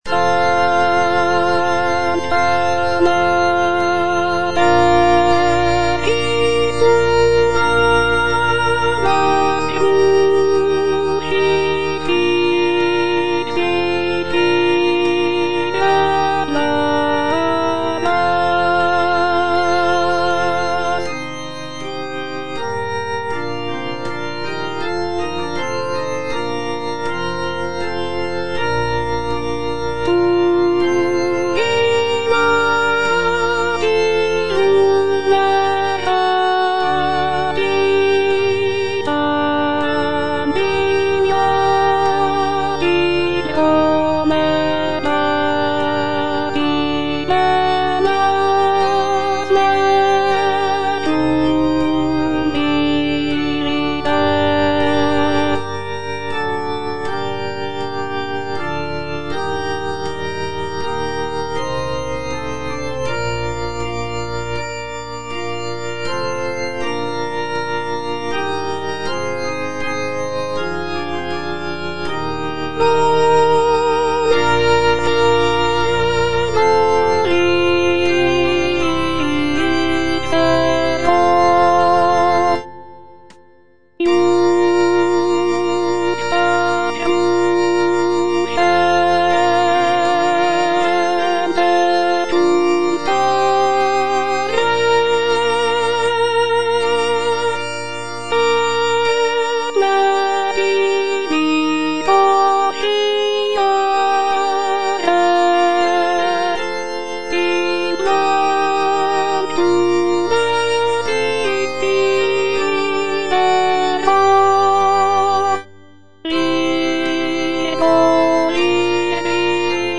(alto I) (Voice with metronome) Ads stop
is a sacred choral work